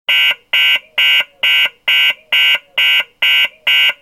lwsiren-buzz.ogg